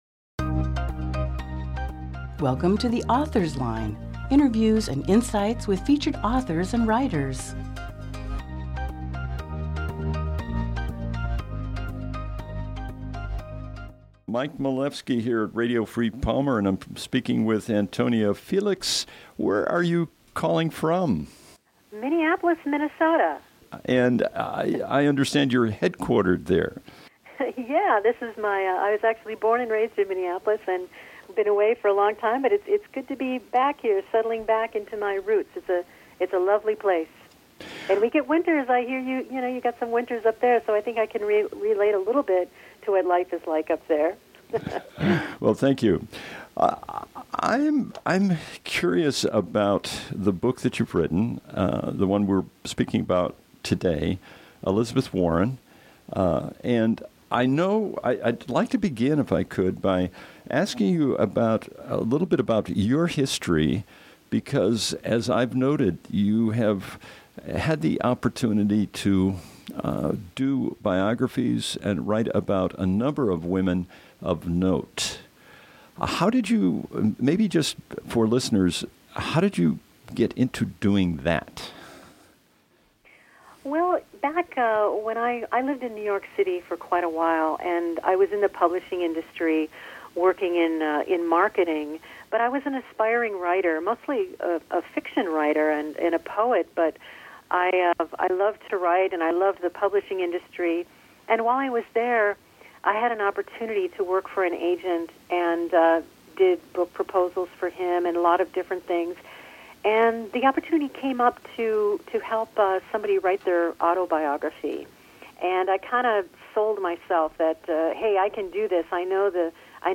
Sep 5, 2018 | Author Interviews